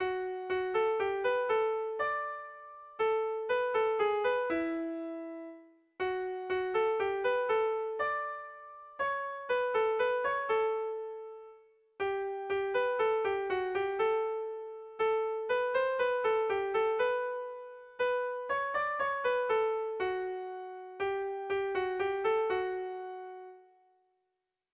Erlijiozkoa
ABDE